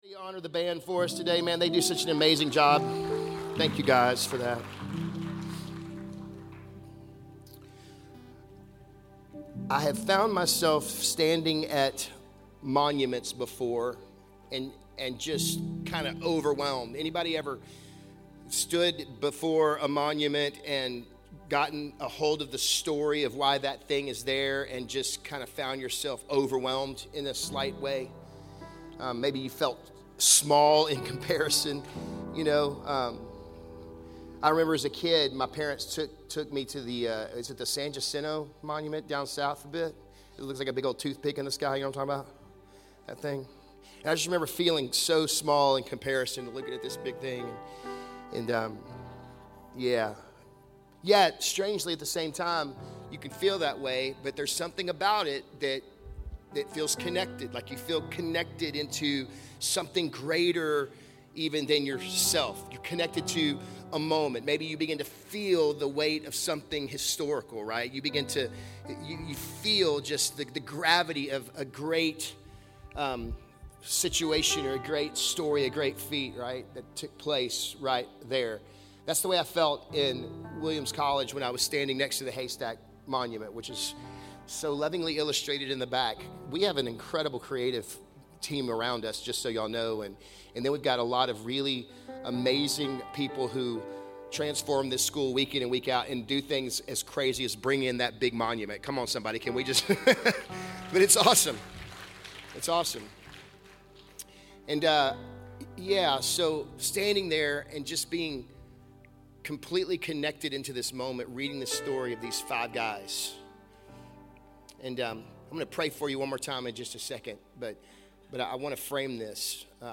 Religion Christianity Church Spring Texas Nondenominational Declaration Church Sermons Spiritual Beings Guide Questions Jesus Help Holy Spirit Information Content provided by Declaration Church.